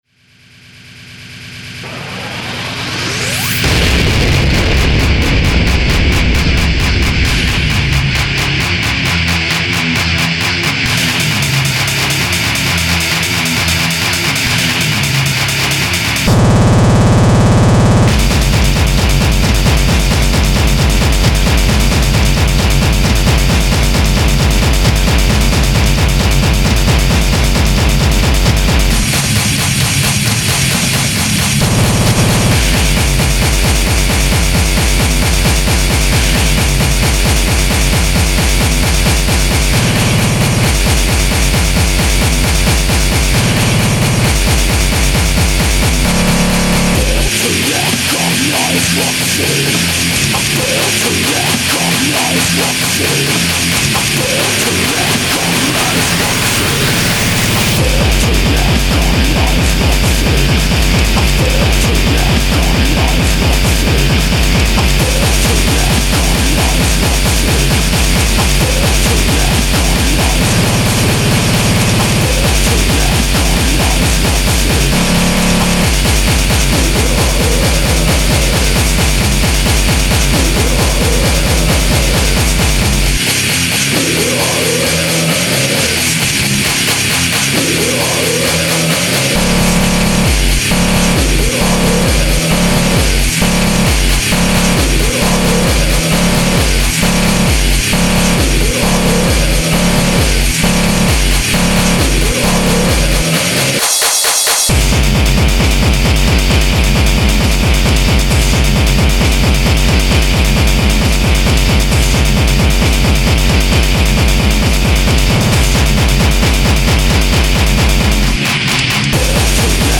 Another Obligatory Grindcore Entry